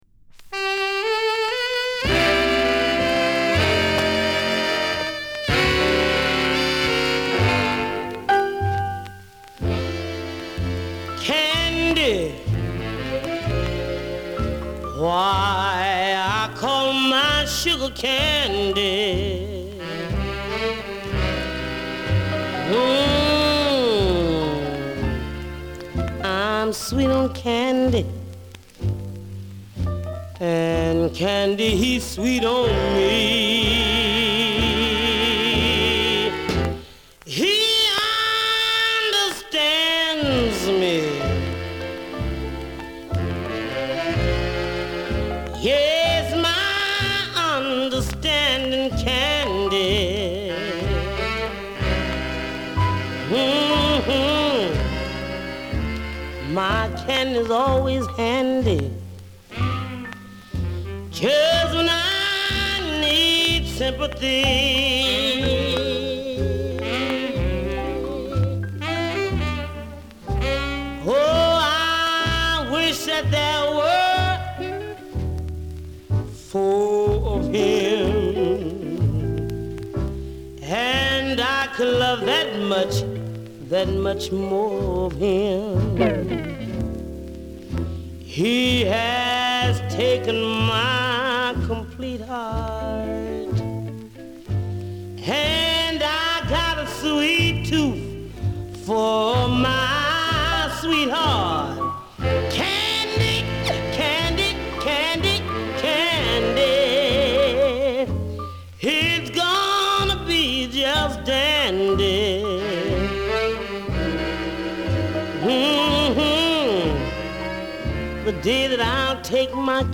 細かいスクラッチの影響で所々でノイズがはいります。